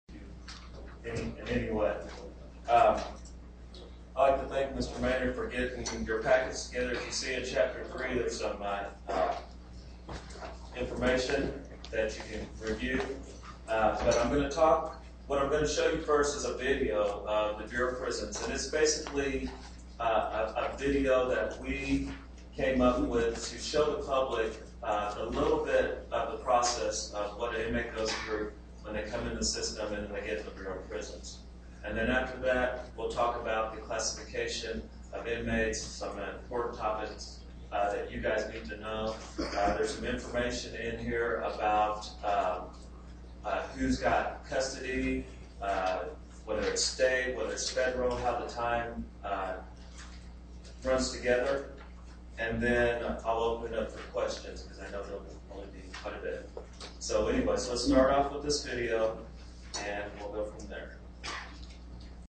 The MP3 file below is a 1 minute example of recording a CLE presentation on your notebook computer while you use it to take notes and access wireless Internet content making your CLE experience more productive and allowing your review later at your own pace.